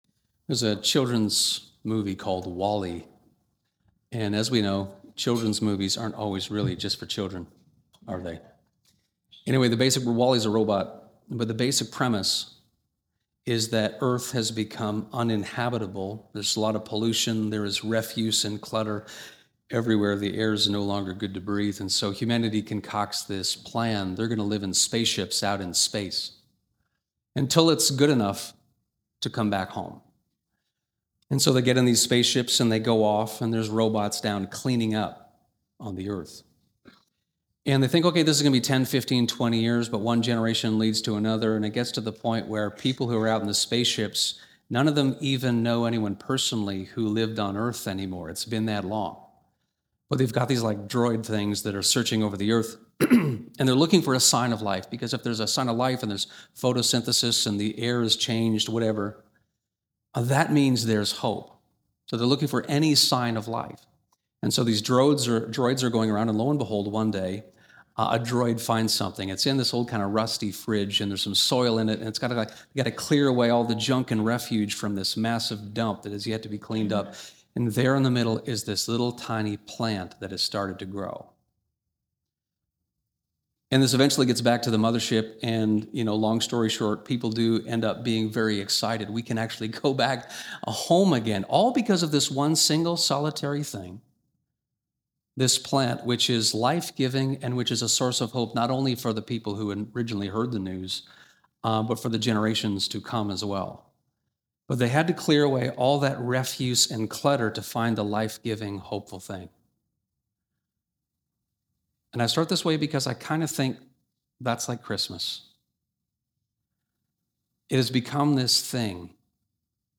Sermons | Westminster
How do we sweep away the consumerism, busyness, distraction and unrealistic expectations and re-ground ourselves in what it’s all about? This Christmas Eve message zeroes in on a surprising text: the parable of the prodigal son.